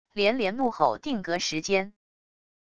连连怒吼定格时间wav音频